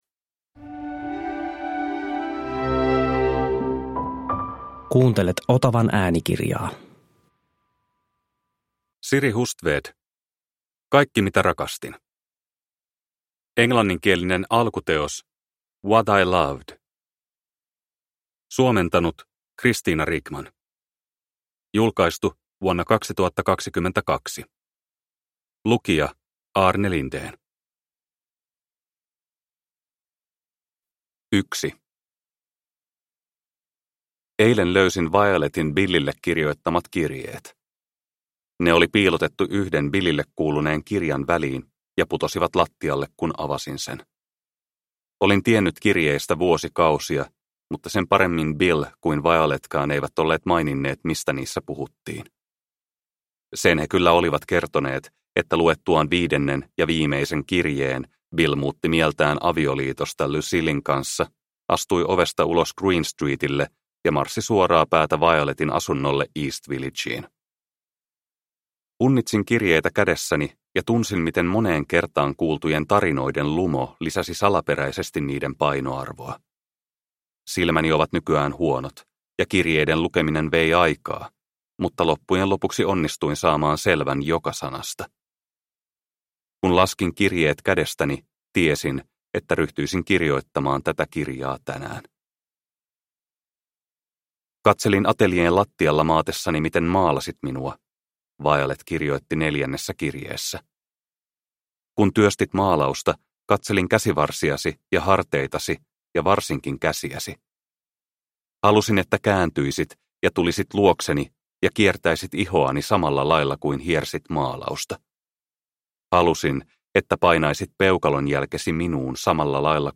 Kaikki mitä rakastin – Ljudbok – Laddas ner